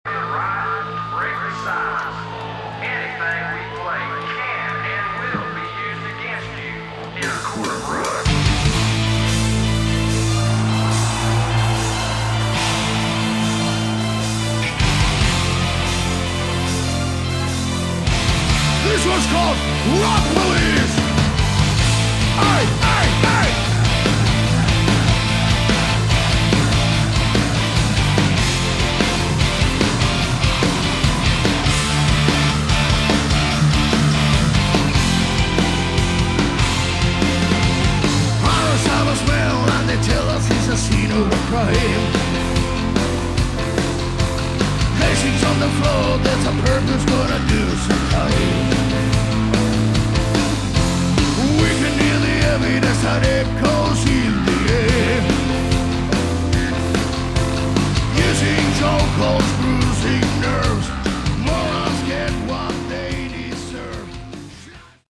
Category: Theatre Metal
vocals
guitars
bass
drums
keyboards